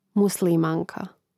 muslìmānka muslimanka